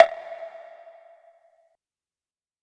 TM-88 Snare #06.wav